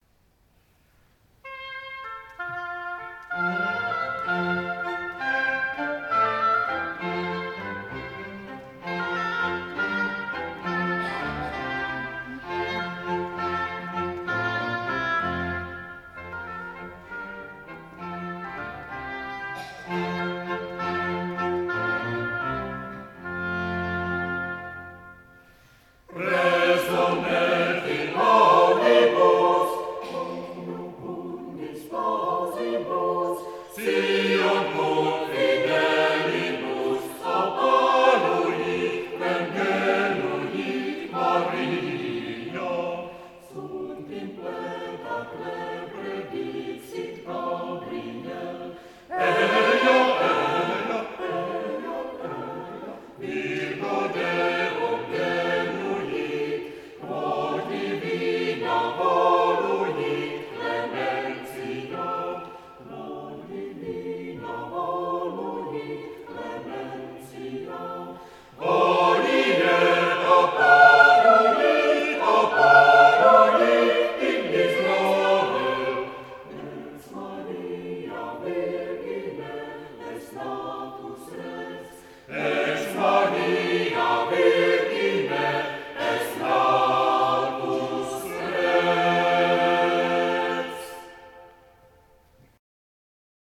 The list origins from concerts performed from 1971.